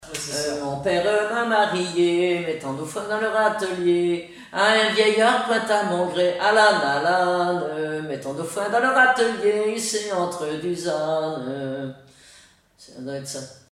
Genre laisse
chansons et témoignages parlés
Catégorie Pièce musicale inédite